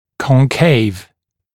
[kɔŋ’keɪv][кон’кейв]вогнутый, впалый